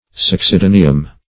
Succedaneum \Suc`ce*da"ne*um\, n.; pl. Succedanea.